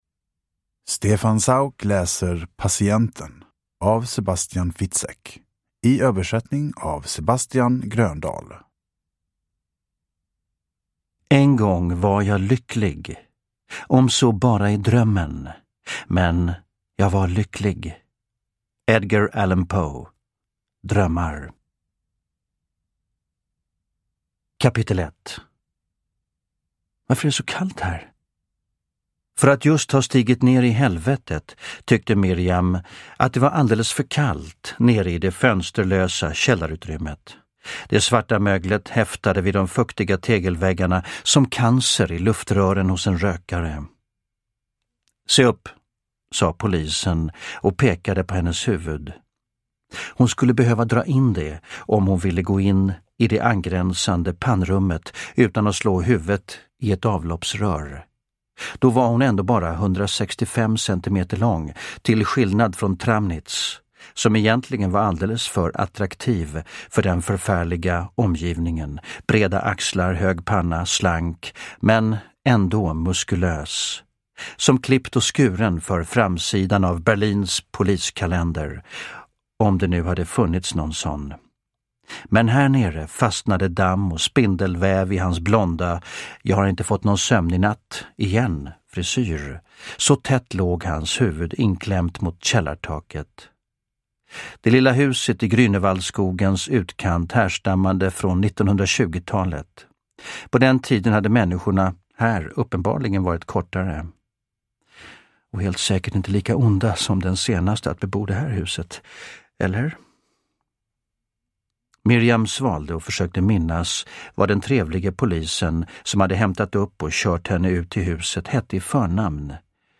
Patienten – Ljudbok – Laddas ner
Uppläsare: Stefan Sauk